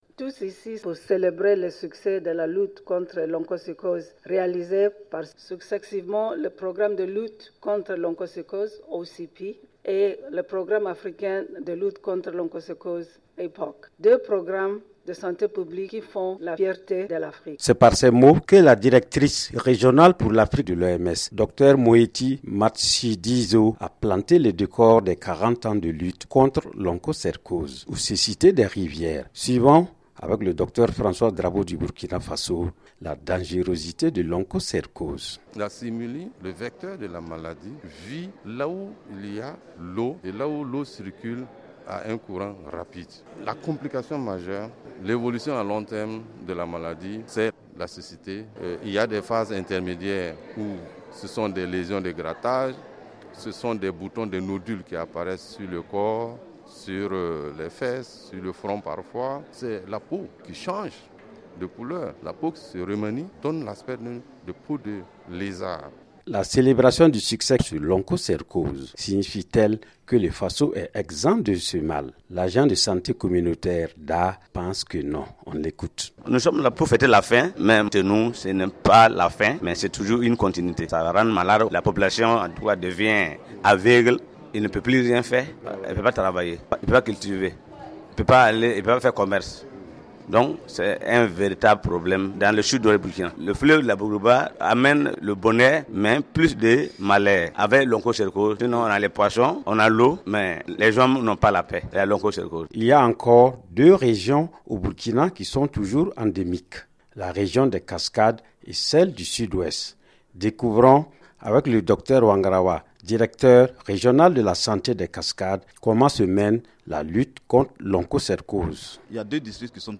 Le ministre tchadien de la santé, Assane Ngueadoum; par ailleurs président du comité regional de l'oms assure que la lutte doit continuer pour vaincre d'autres maladies.
Reportage